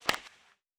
Foley Sports / Hockey / Puck Hit Distant.wav
Puck Hit Distant.wav